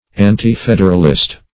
Meaning of anti-federalist. anti-federalist synonyms, pronunciation, spelling and more from Free Dictionary.
Search Result for " anti-federalist" : The Collaborative International Dictionary of English v.0.48: Anti-federalist \An`ti-fed"er*al*ist\, n. One of party opposed to a federative government; -- applied particularly to the party which opposed the adoption of the constitution of the United States.